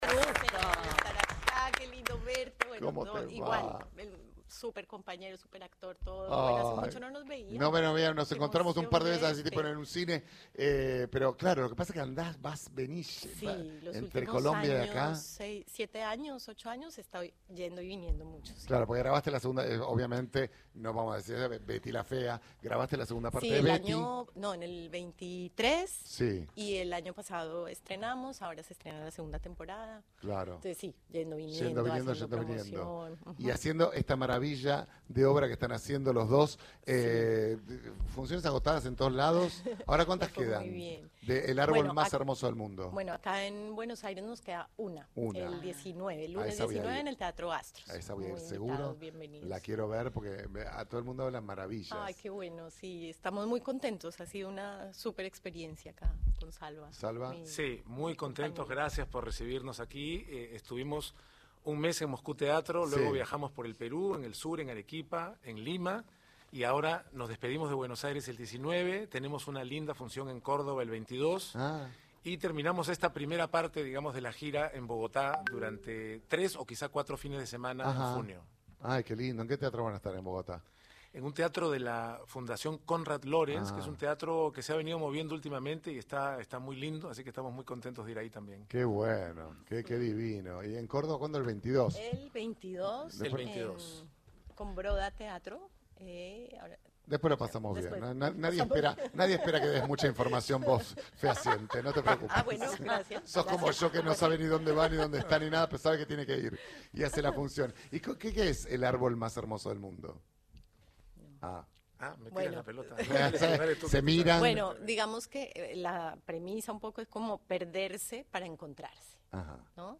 Ana María Orozco, la reconocida protagonista de la telenovela Betty La Fea, junto al actor peruano Salvador del Solar, visitaron el estudio de Nacional AM 870, donde hablaron con el equipo de Diego Ramos de la obra de teatro El árbol más hermoso del mundo, que presentarán el 19 de mayo en el Teatro Astros.